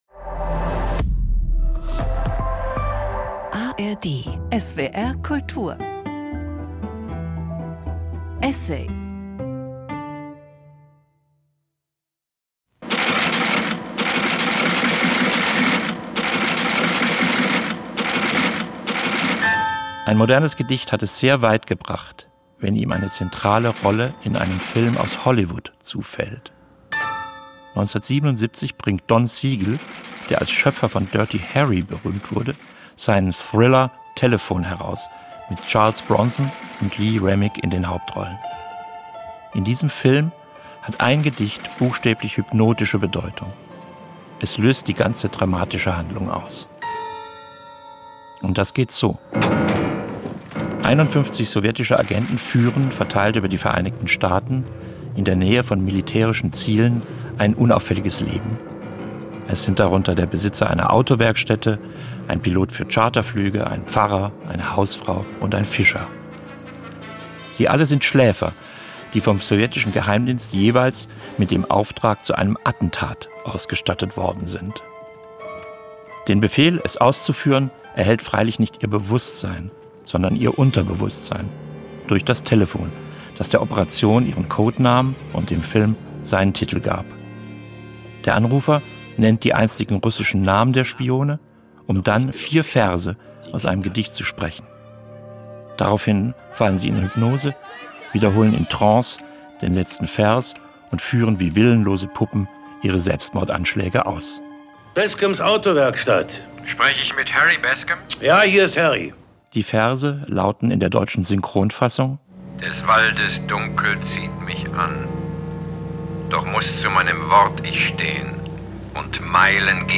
Jürgen Kaube spricht über die Bedeutung des vielleicht berühmtesten Gedichtes der amerikanischen Literatur.